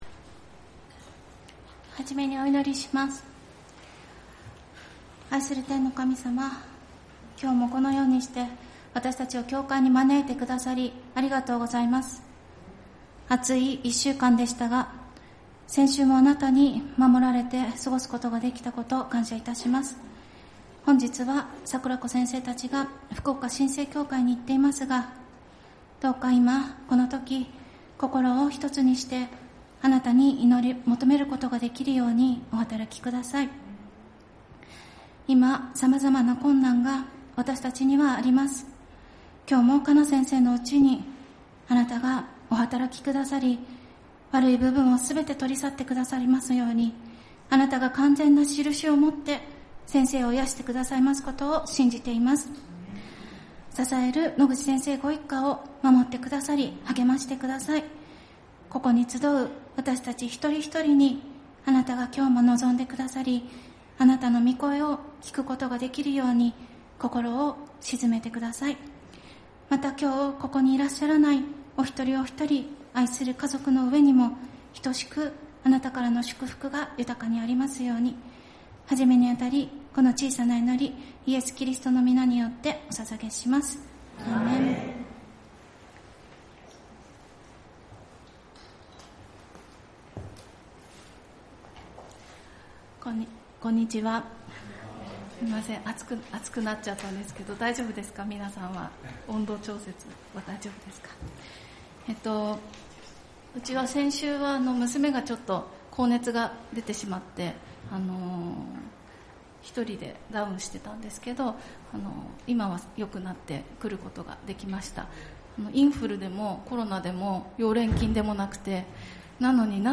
聖日礼拝「自らを誇らず主の愛に生きる」